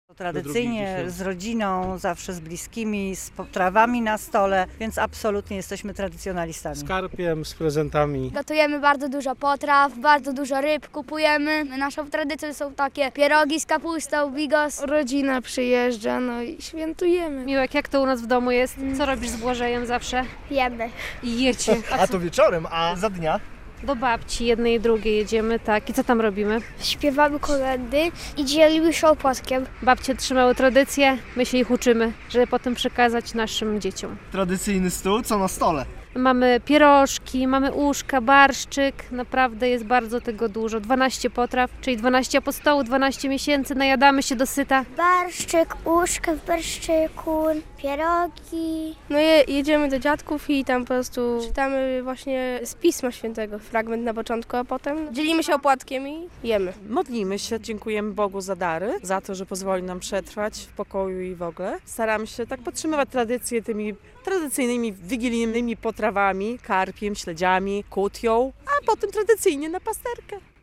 Słuchaj: Jak białostoczanie spędzają Wigilię? - sonda